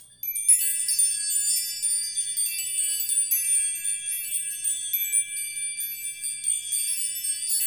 Index of /90_sSampleCDs/Roland - Rhythm Section/PRC_Asian 2/PRC_Windchimes